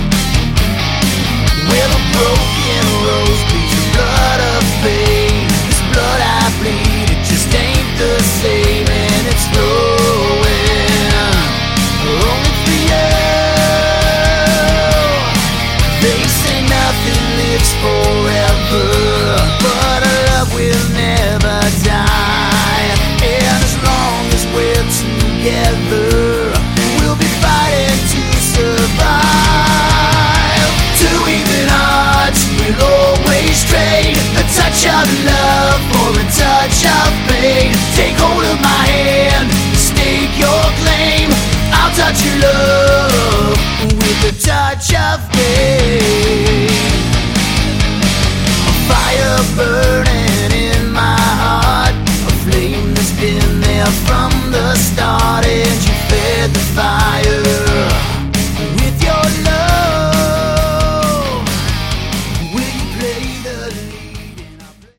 Category: Melodic Hard Rock
Lead Vocals, Bass, 12 String Guitar
Drums, Percussion, Backing Vocals
Guitars, Background Vocals